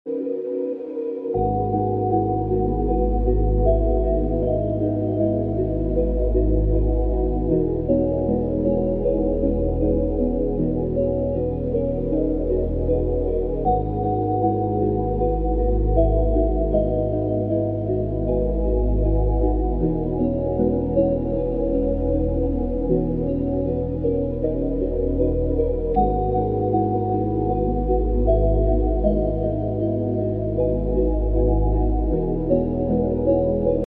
ambient sound blue archive 😌🥀 sound effects free download